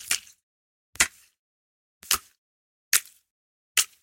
Звуки клея
Клейкие брызги